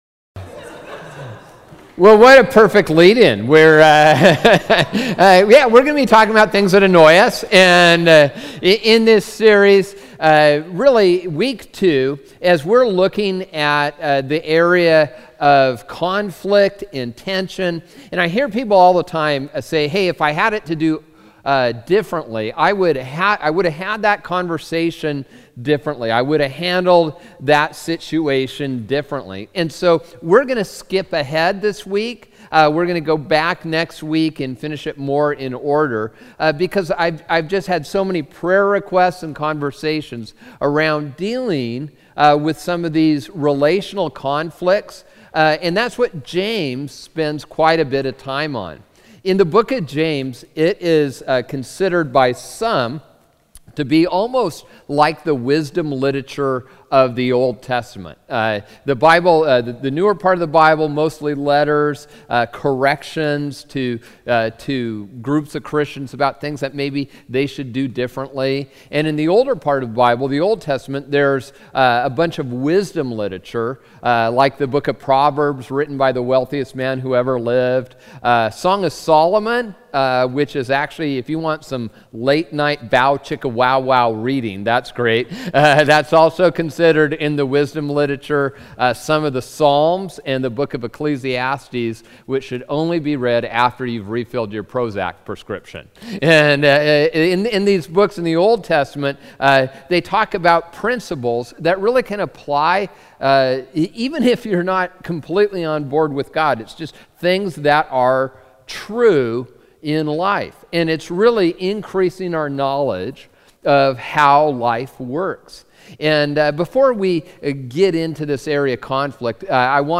Date: 01/11/2015 Message Begins at 18:01